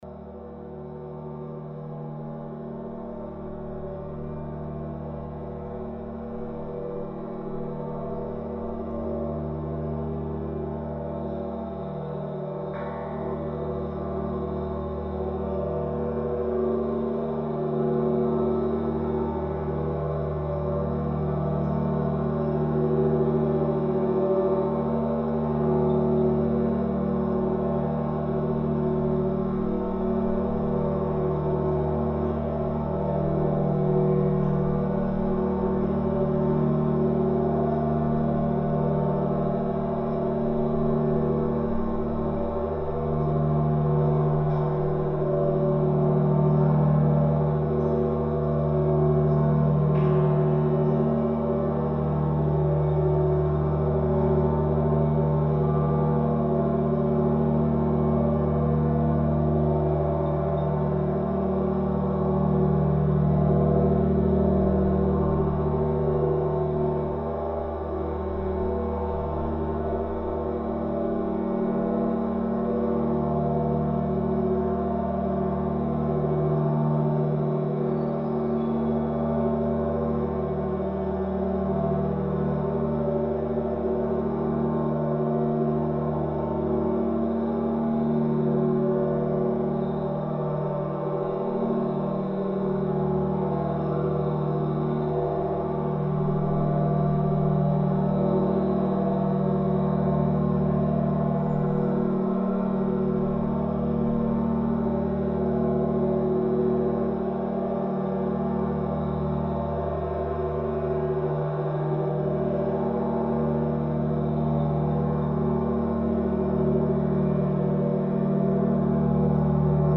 SILENT ECHOES OF THE VATICAN"S GREAT BELL SOUNDING IN THE
I began to explore this phenomenon using high resolution vibration sensors called accelerometers. These may be placed onto and inside of a wide range of structures and situations that then map, render and reveal the silent echoes latent in a structure or an object that is echoing a live soundscape.
SILENT_ECHOES_OF_THE_CAMPANONE.mp3